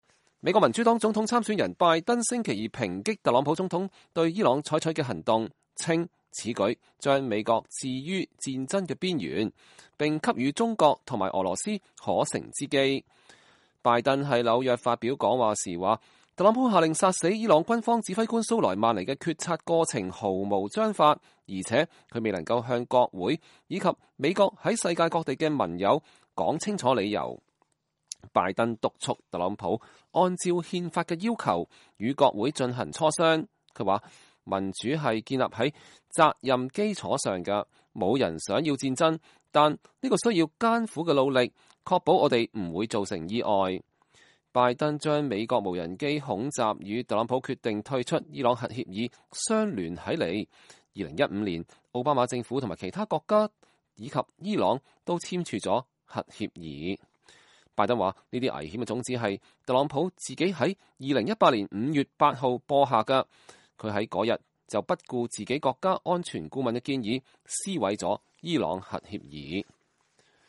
美國民主黨總統參選人拜登在紐約發表演講（2020年月7日）。